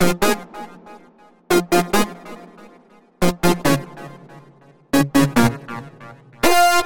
描述：y 恍惚或舞蹈合成器
Tag: 139 bpm Dance Loops Synth Loops 1.15 MB wav Key : Unknown